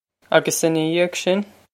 Pronunciation for how to say
A-gus inna yee-og shin?
This is an approximate phonetic pronunciation of the phrase.